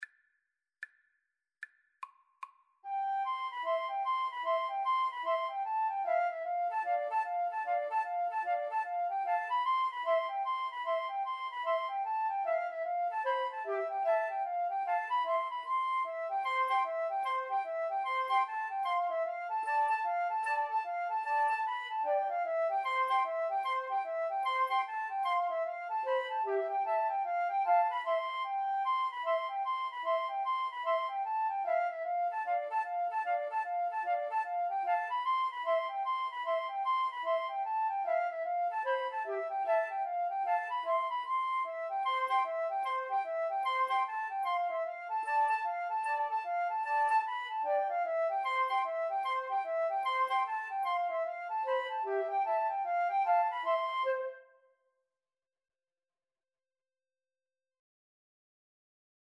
C major (Sounding Pitch) (View more C major Music for Flute Trio )
Flute Trio  (View more Intermediate Flute Trio Music)